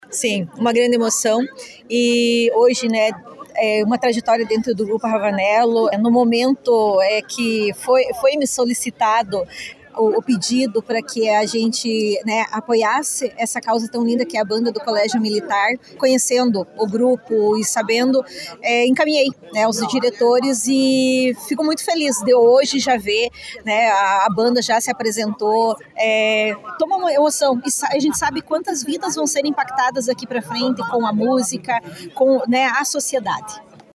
Em União da Vitória, a data foi celebrada com uma solenidade especial realizada nas dependências do 27º Batalhão de Polícia Militar, reunindo autoridades civis e militares, além de familiares e convidados.